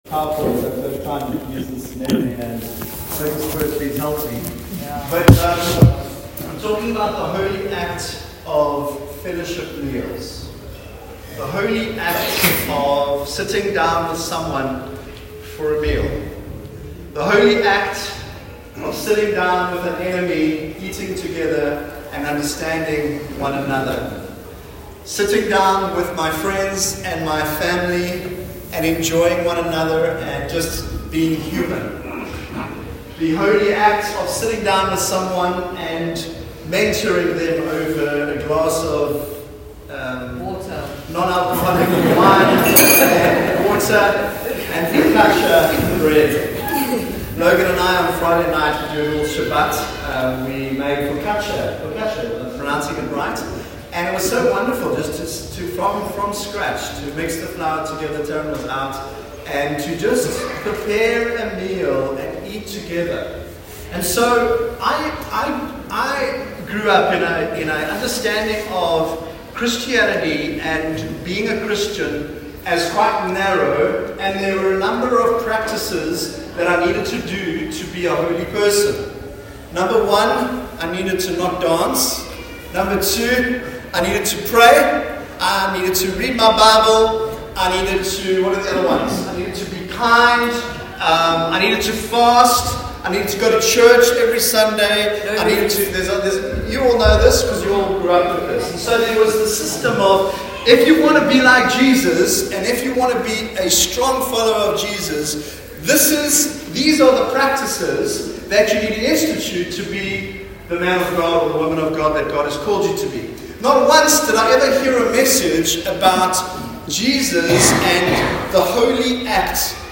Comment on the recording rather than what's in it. Sunday Service – 27 July